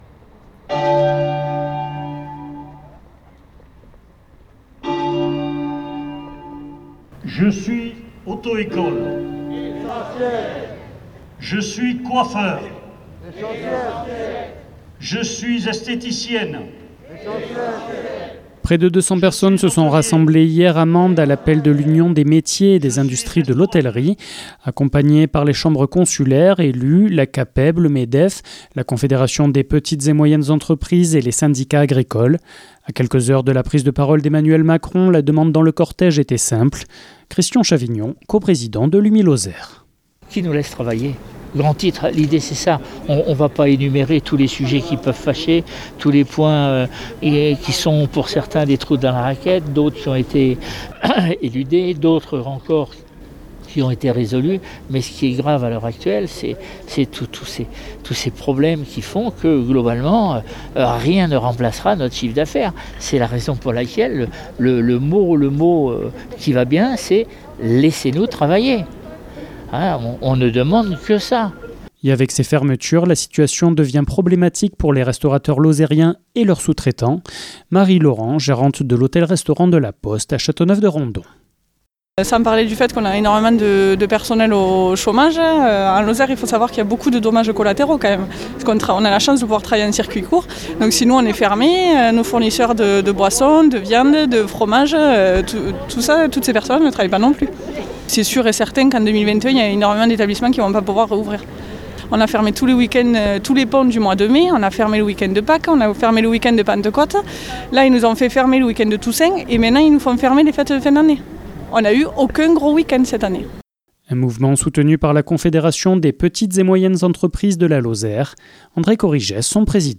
Comme partout en France une manifestation a eu lieu hier dans les rues de Mende pour protester contre la fermeture des métiers de l’hôtellerie et des commerces non essentiels.
Manifestation UMIH